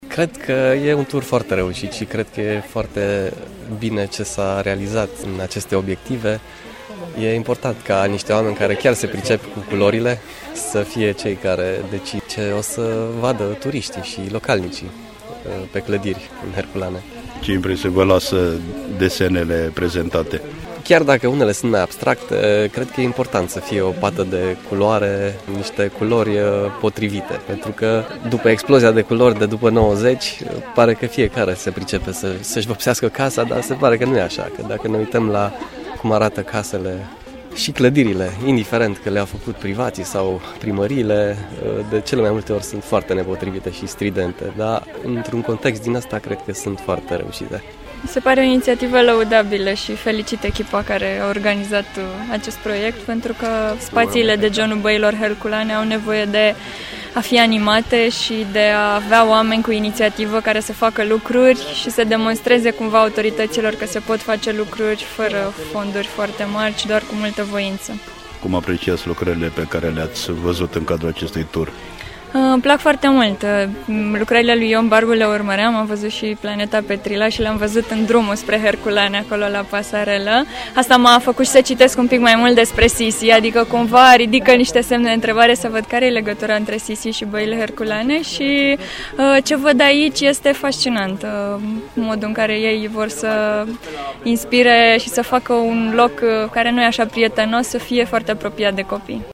La sfârșitul turului, câțiva turiști ne-au spus impresiile lor despre ceea ce au văzut că s-a lucrat în această săptămână în orașul de pe Valea Cernei :
bai-turisti-Sibiu-si-Curtea-de-Arges.mp3